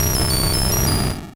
Cri de Magnéton dans Pokémon Rouge et Bleu.